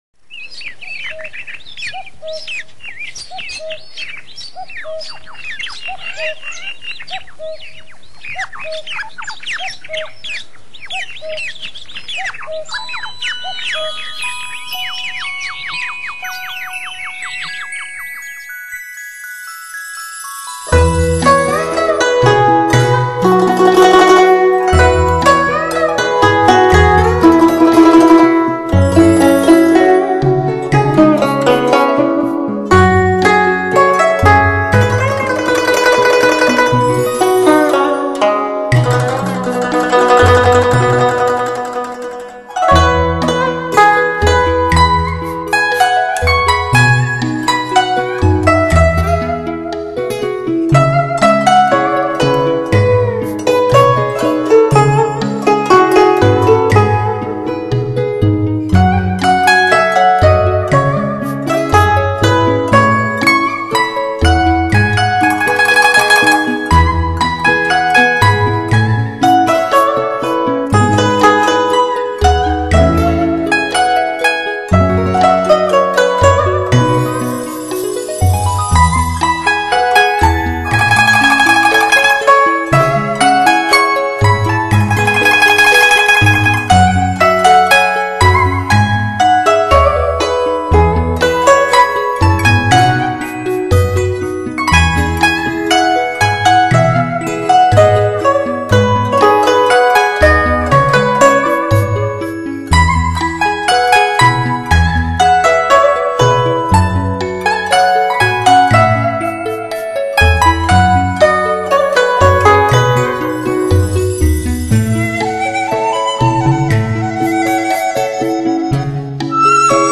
古筝